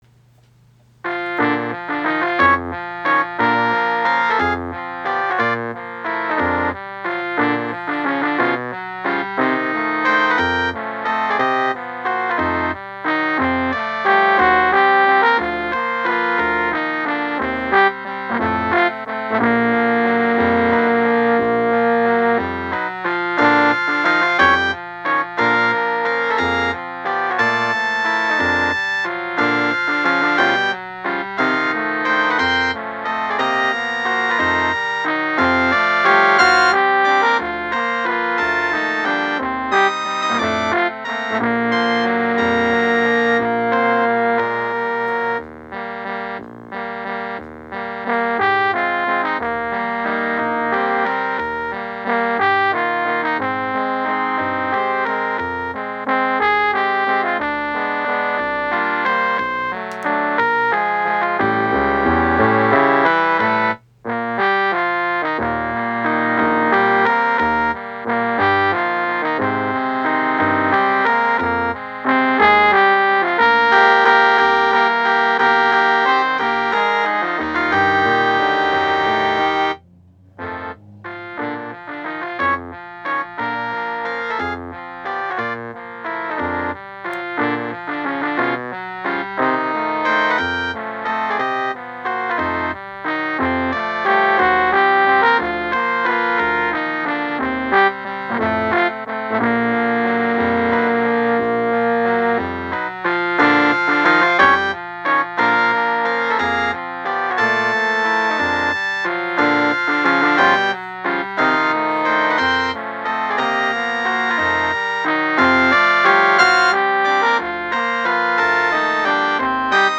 A Day at the Friary, for concert band, written for the Friary Guildford Band.